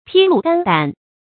披露肝胆 pī lù gān dǎn
披露肝胆发音
成语注音ㄆㄧ ㄌㄨˋ ㄍㄢ ㄉㄢˇ